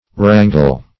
Search Result for " rangle" : The Collaborative International Dictionary of English v.0.48: Rangle \Ran"gle\ (r[a^]n"g'l), v. i. To range about in an irregular manner.